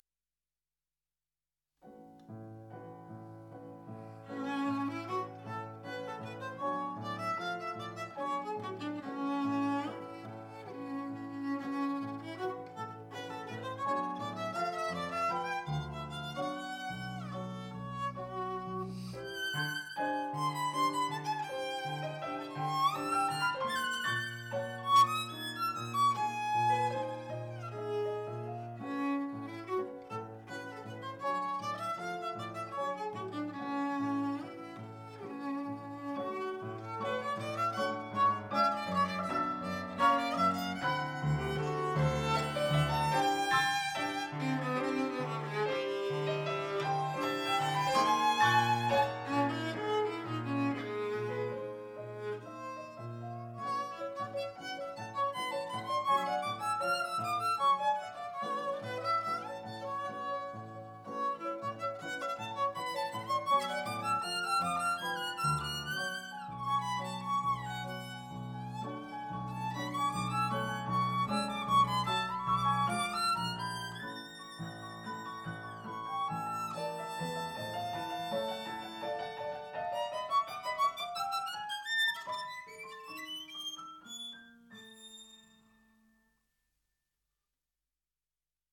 古典音樂/發燒天碟
violin
piano